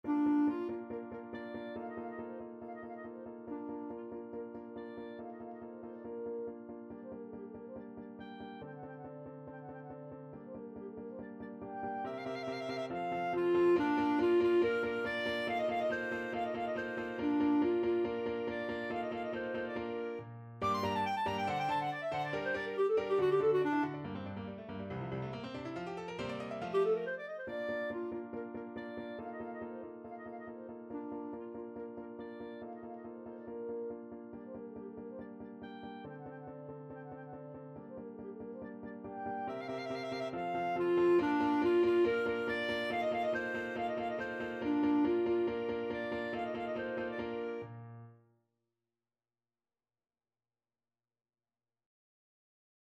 Clarinet
2/4 (View more 2/4 Music)
Bb major (Sounding Pitch) C major (Clarinet in Bb) (View more Bb major Music for Clarinet )
~ = 140 Allegro vivace (View more music marked Allegro)
Classical (View more Classical Clarinet Music)